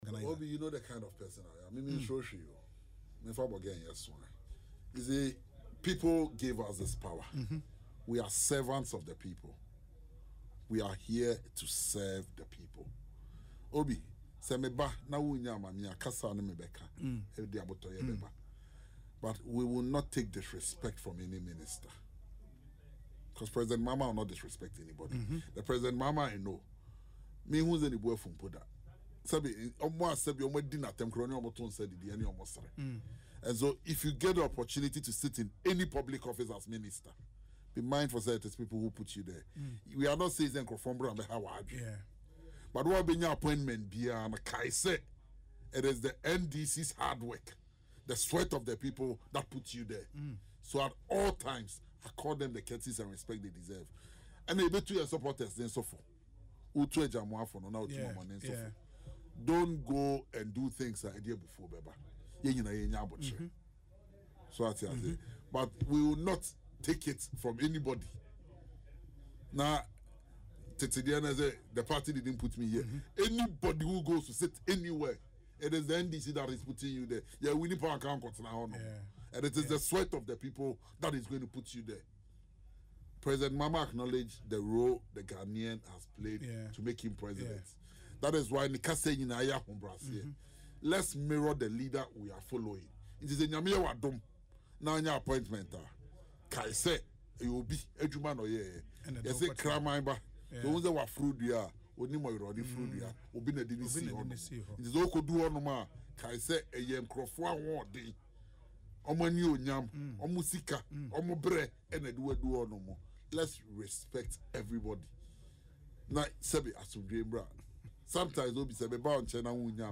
In an interview on Asempa FM’s Ekosii Sen, he emphasised the need not to take the people for granted, stating the appointees would have no jobs but for the unwavering support from the electorate.